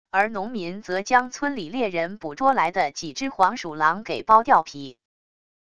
而农民则将村里猎人捕捉来的几只黄鼠狼给剥掉皮wav音频生成系统WAV Audio Player